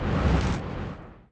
1 channel